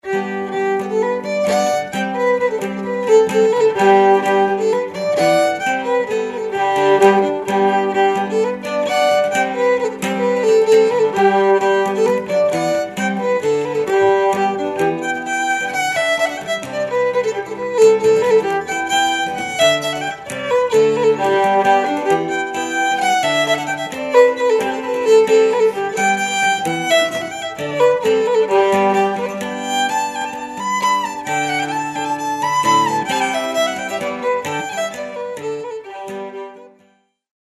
traditional Irish polkas
benefit-polkas-short.mp3